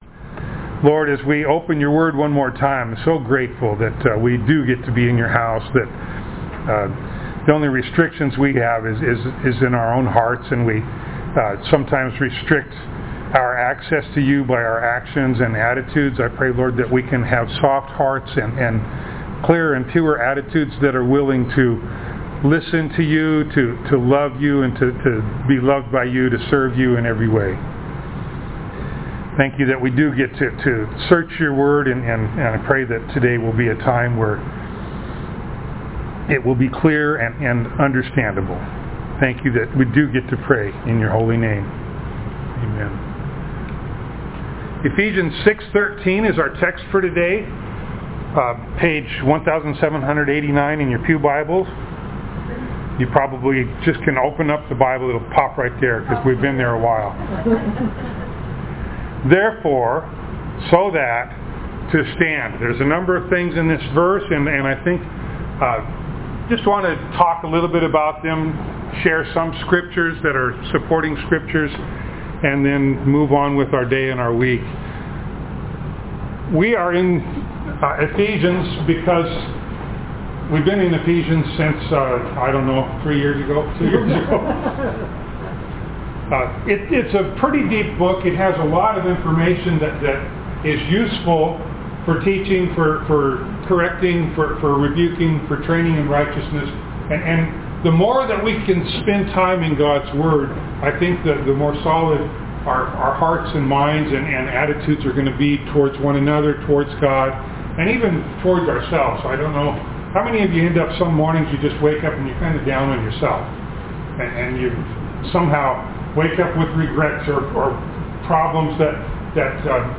Ephesians Passage: Ephesians 6:13 Service Type: Sunday Morning Download Files Notes « Rulers